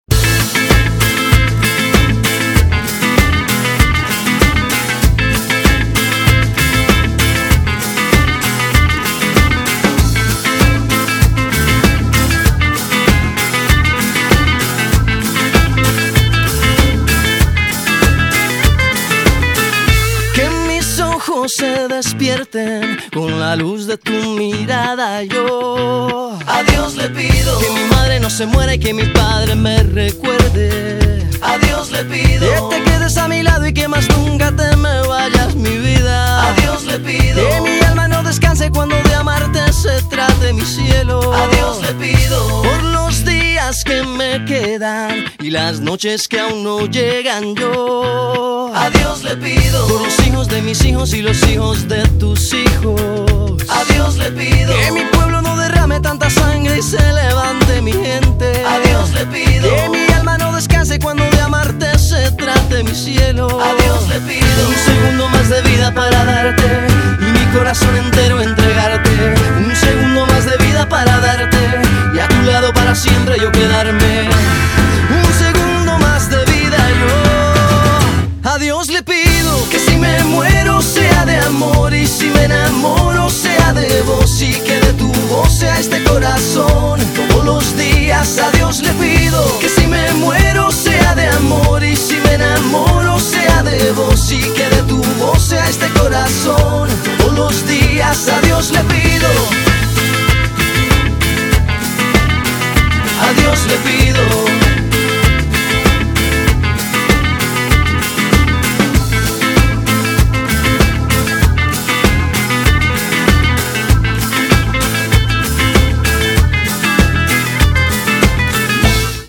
BPM97
Audio QualityMusic Cut